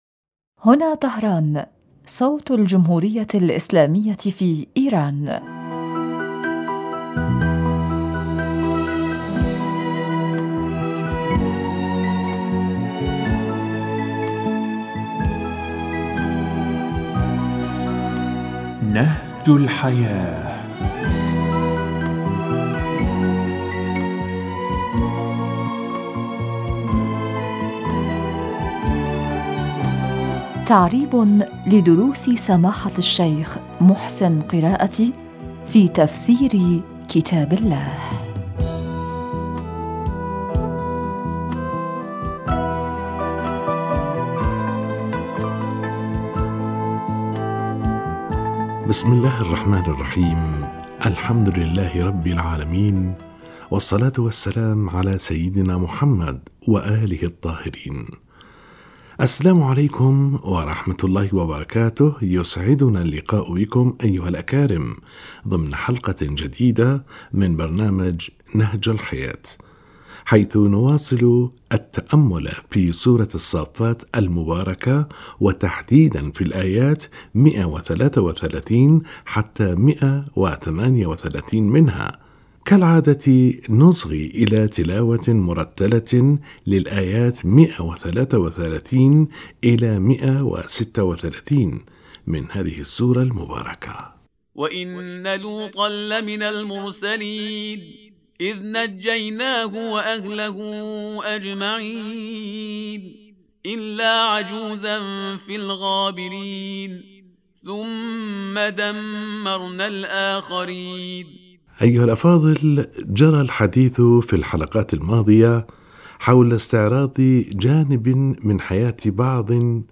السلام عليكم و رحمة الله وبركاته يسعدنا اللقاء بكم أيها الأكارم ضمن حلقة جديدة من برنامج نهج الحياة حيث نواصل التأمل في سورة الصافات المباركة و تحديدا في الايات133 حتى 138 منها . كالعادة نصغي إلى تلاوة مرتلة للايات 133 إلى 136 من هذه السورة المباركة: